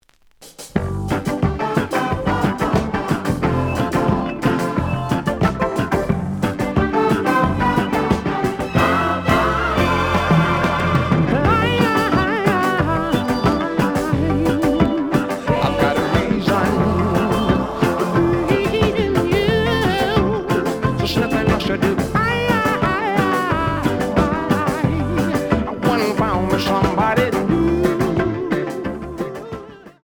(Mono)
試聴は実際のレコードから録音しています。
●Genre: Funk, 70's Funk